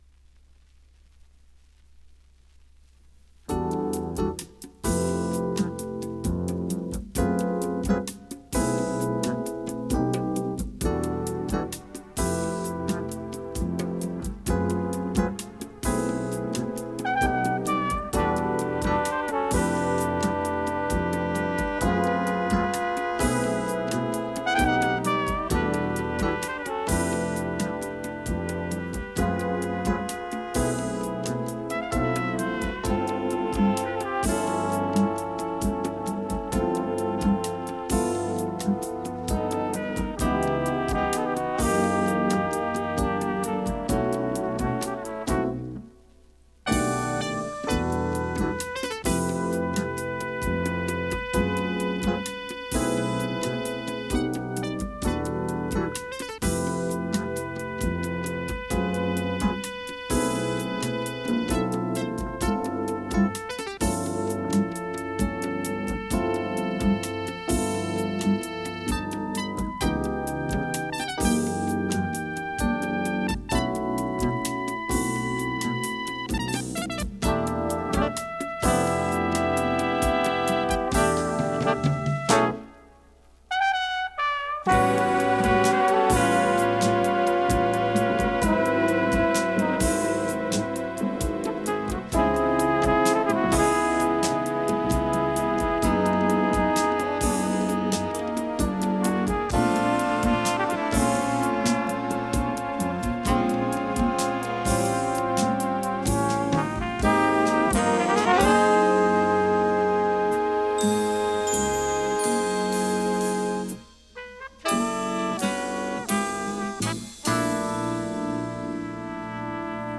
(Jazz)